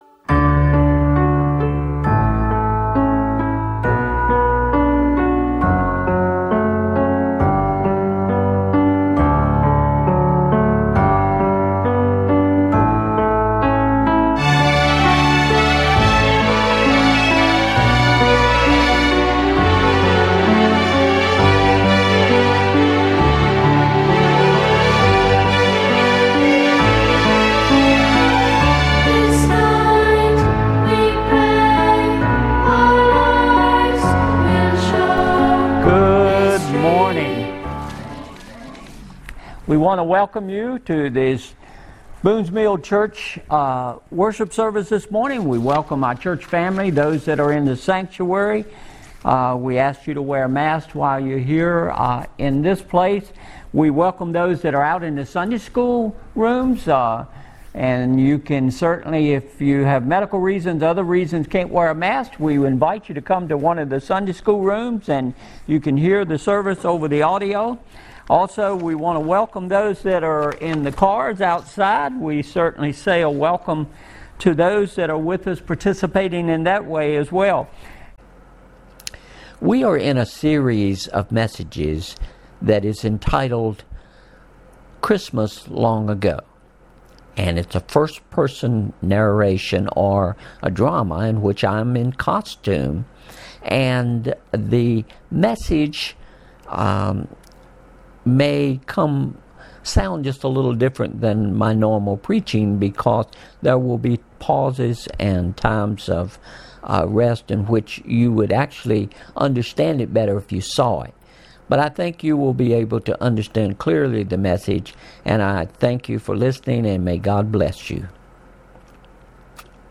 (A first person narration/drama - by Joseph)